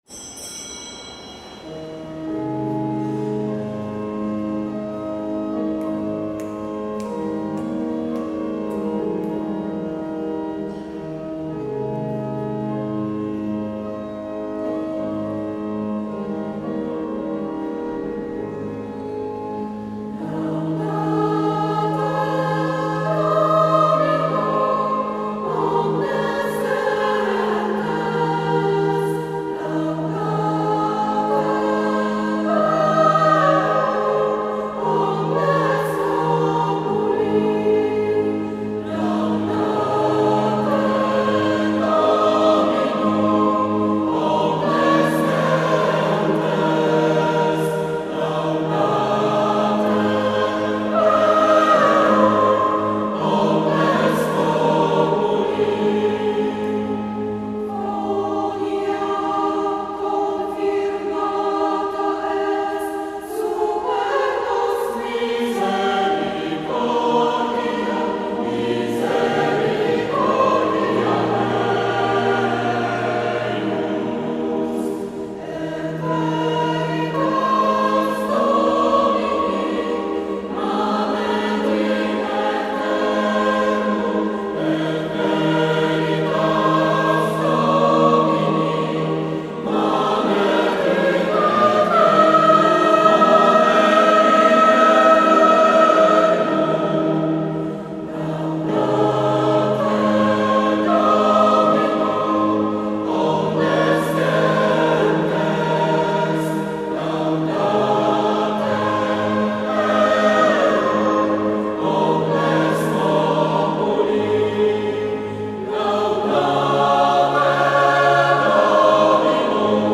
♫ Posługa w Uroczystość Bożego Ciała (31 maja 2018)
♫ Posługa w Uroczystość Bożego Ciała (31 maja 2018) Cantores Beati Vincentii Opublikowano w 31 maja 2018 przez ≡ 17 stycznia 2019 ♪ Uroczystość Bożego Ciała Laudate dominum Alleluja!